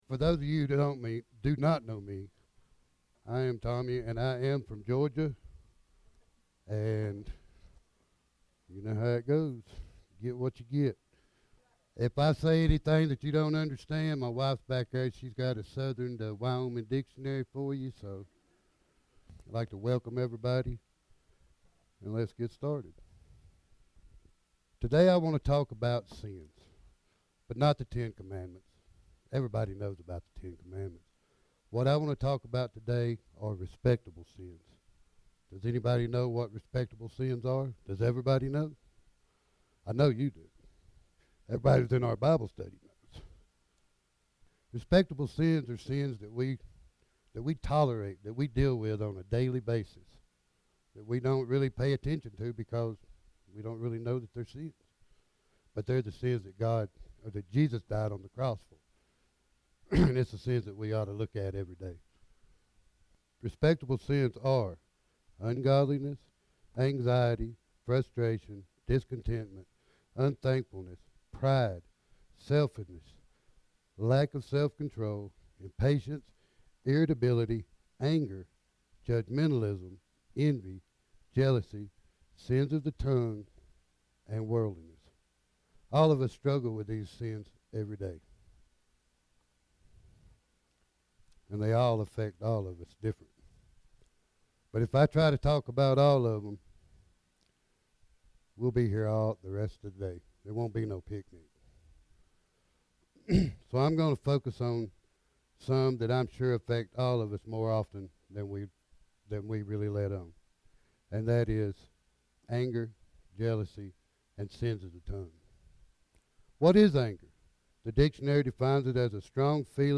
SERMON: Respectable Sins – Church of the Resurrection